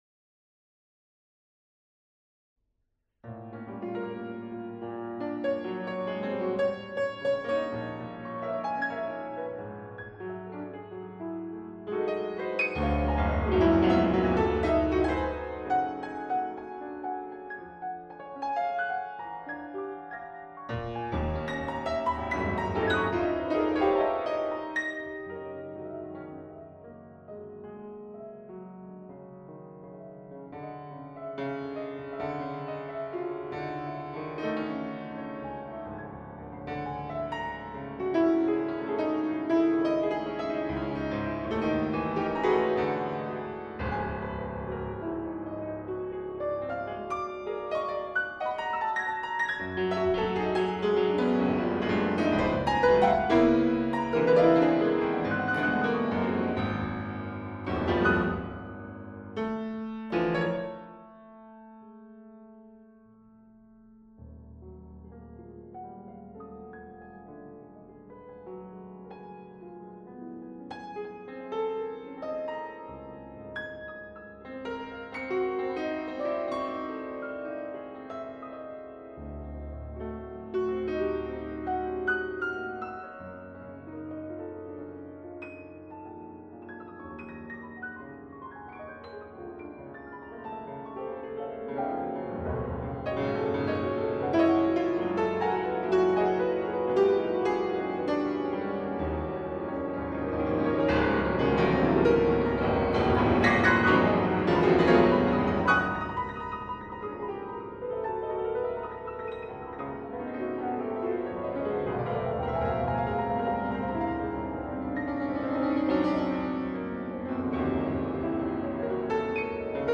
for two pianos; 1° piano*, 2° piano**
Recording DDD of Centro Studi Assenza; jan. 2000
08improvvisazione.mp3